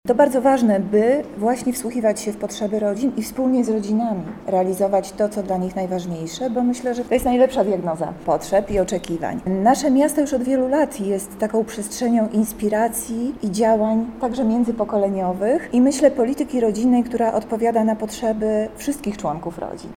-mówi Monika Lipińska, zastępczyni prezydenta Lublina.